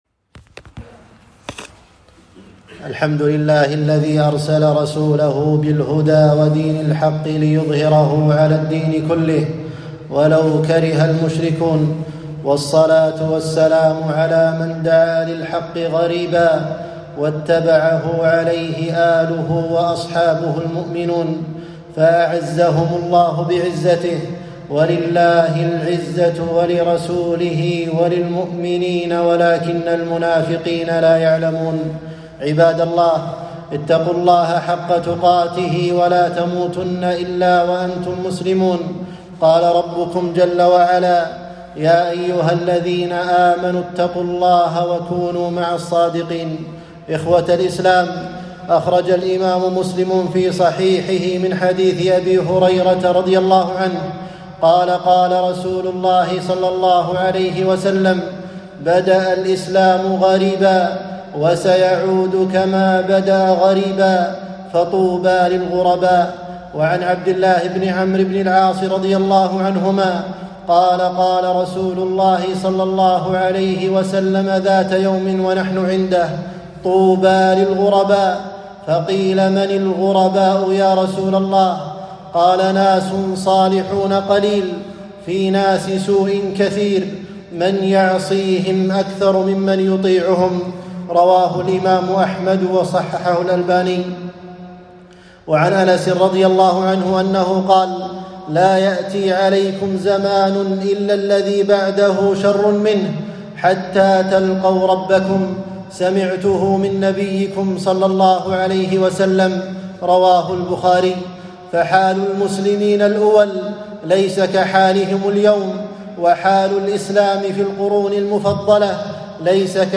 خطبة - غربة الدين وأهله